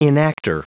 Prononciation du mot enactor en anglais (fichier audio)
Prononciation du mot : enactor